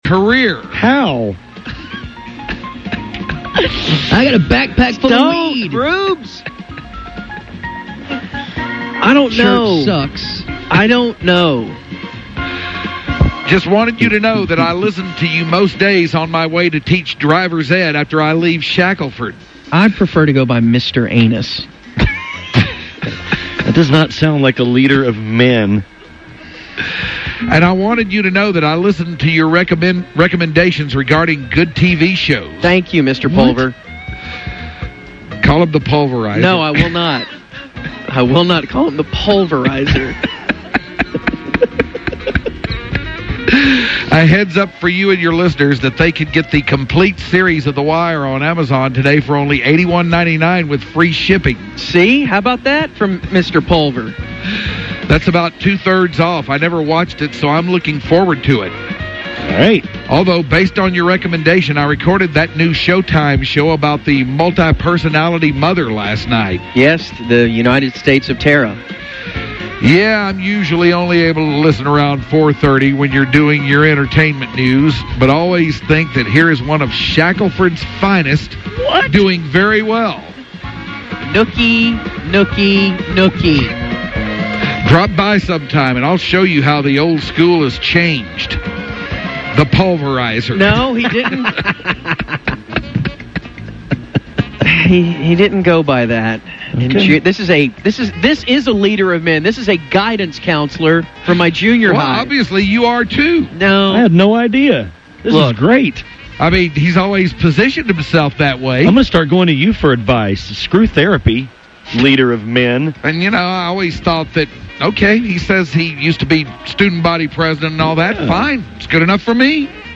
This is the guys reading this message and discussing it. http